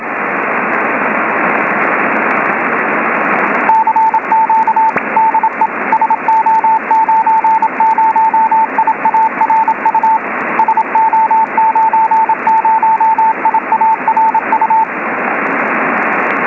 CQ de SO90IARU